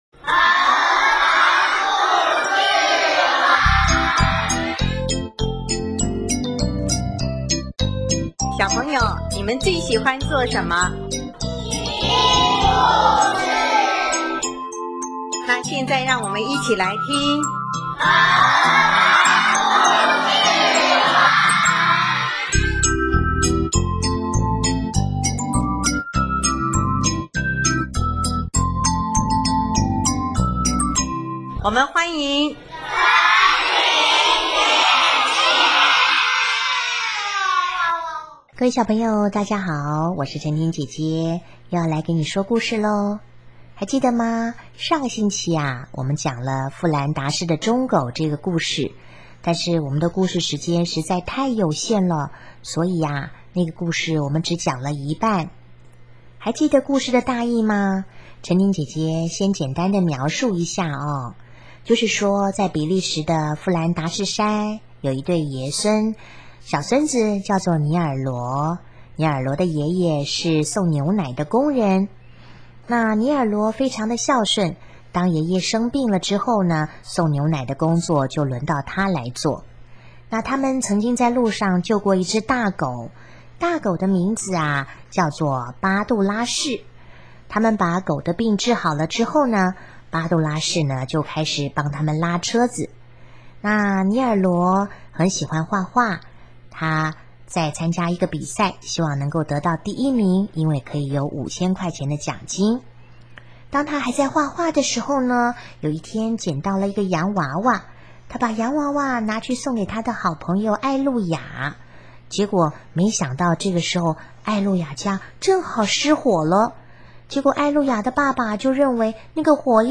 首页 / 家庭/ 儿童故事